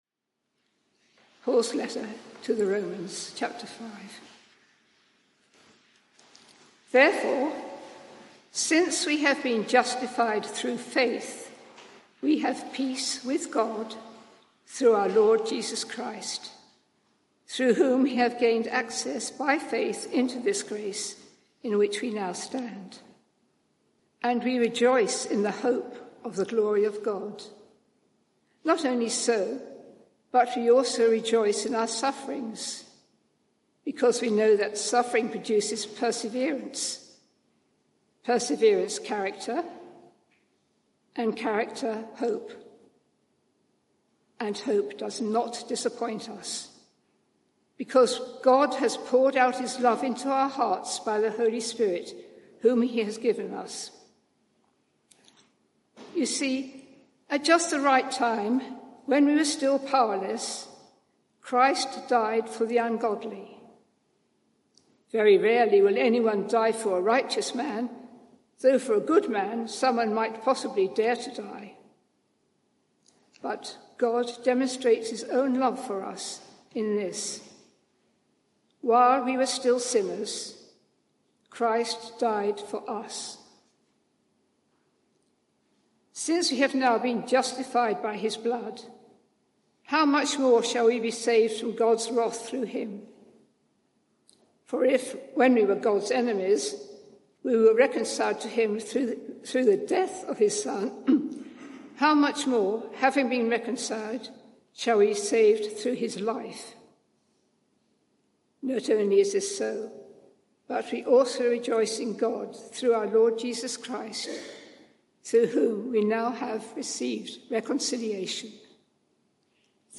Media for 11am Service on Sun 29th Oct 2023 11:00 Speaker
Sermon (audio)